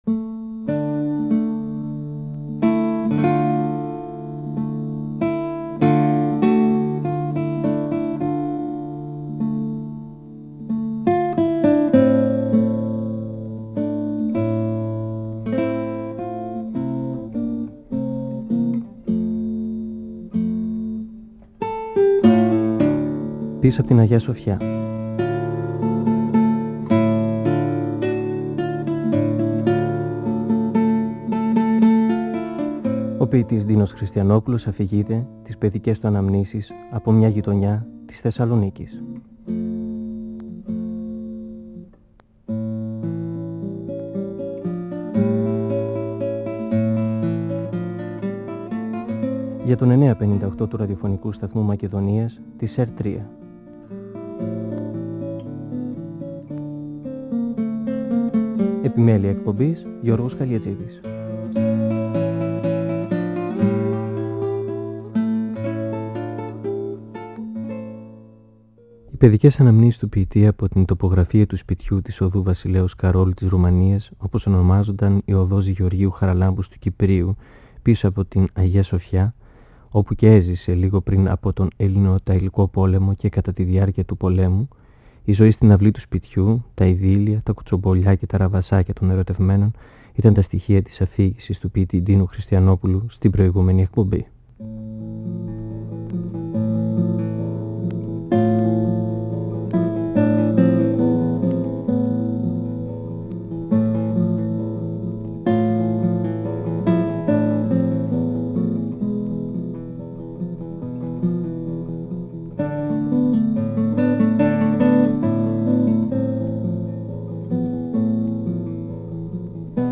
(Εκπομπή 6η) Ο ποιητής Ντίνος Χριστιανόπουλος (1931-2020) μιλά για τις αναμνήσεις του από μια παλιά γειτονιά της Θεσσαλονίκης, πίσω απ’ την Αγια-Σοφιά. Μιλά για τον τρόπο οργάνωσης της ζωής στην αυλή του σπιτιού του. Για τον βυζαντινό τρόπο ζωής, που διατηρήθηκε για χρόνια και χάθηκε μετά τον πόλεμο.